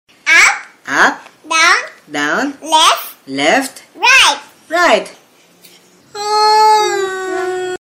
kucing lucu bikin ngakak sound effects free download